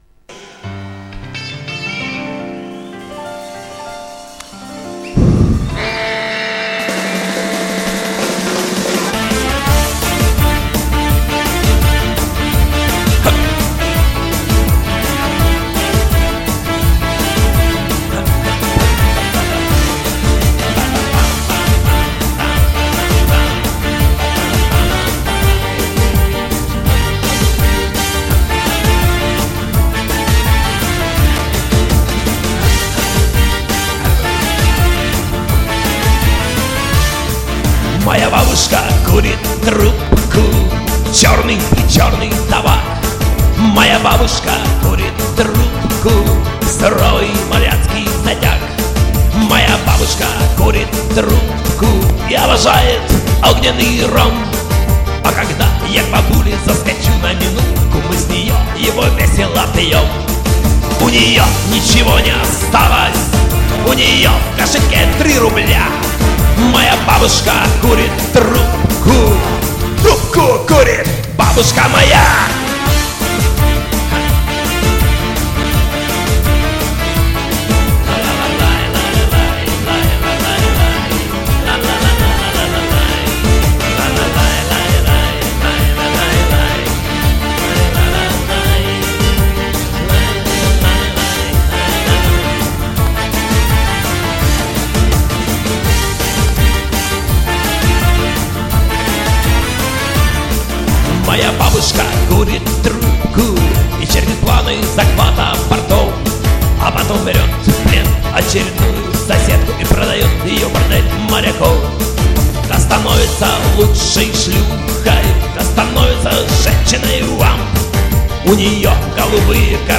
Ощущаются перегруз и помехи от микрофона.